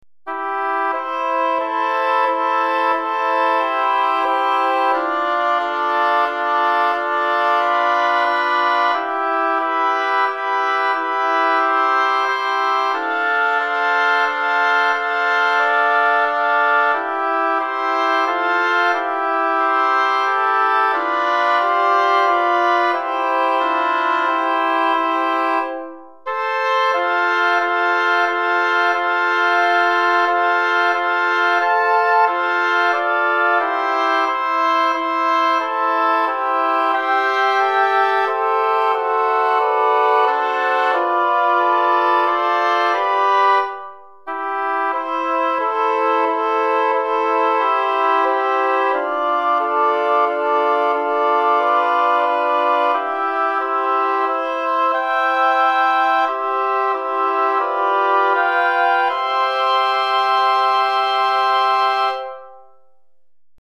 Répertoire pour Hautbois - 4 Hautbois